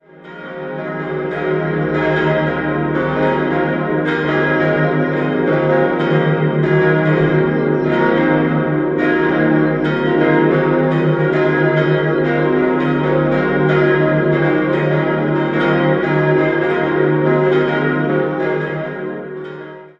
5-stimmiges Geläute: c'-es'-f'-g'-b' Die drei kleinen Glocken wurden 1951 von Engelbert Gebhard in Kempten gegossen.
Bei der größten Glocke handelt es sich um eine Gussstahlglocke des Bochumer Vereins aus dem Jahr 1922.